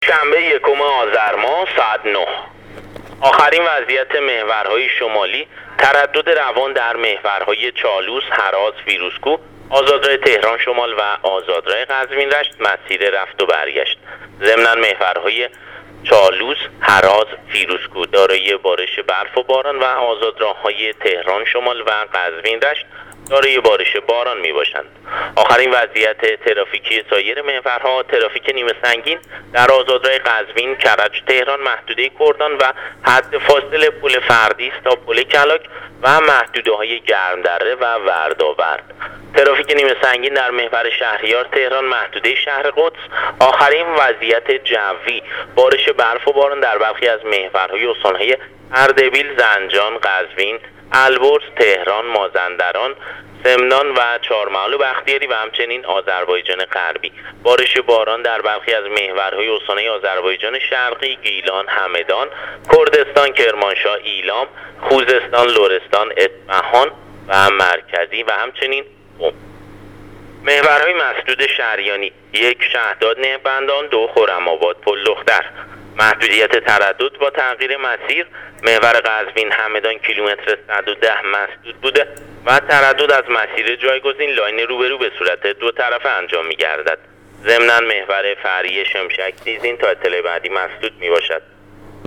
گزارش رادیو اینترنتی از وضعیت ترافیکی جاده‌ها تا ساعت ۹ اول آذر